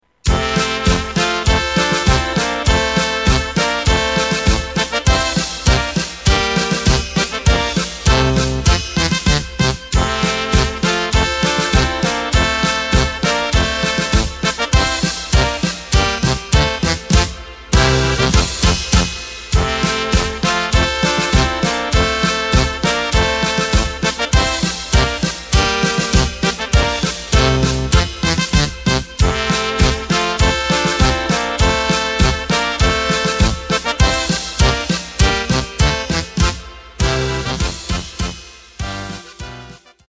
Takt:          2/4
Tempo:         100.00
Tonart:            F
Polka instrumental Blasmusik!